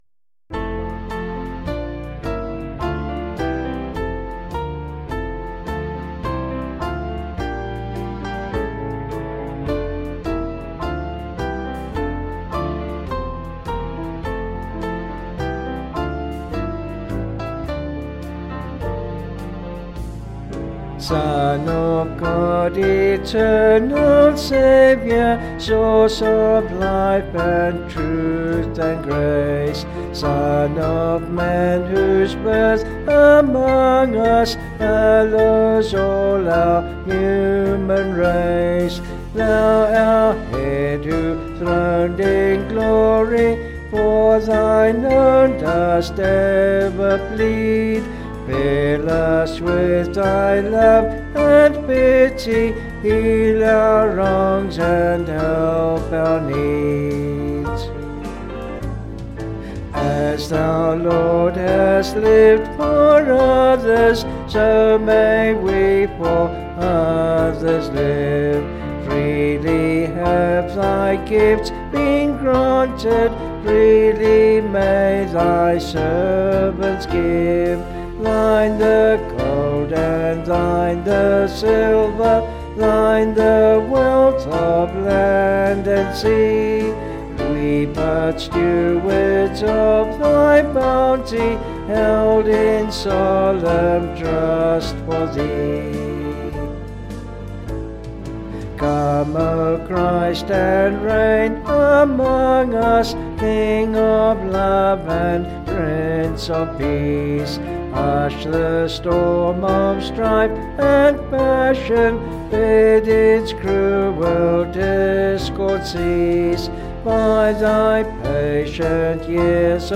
Vocals and Band   265.6kb Sung Lyrics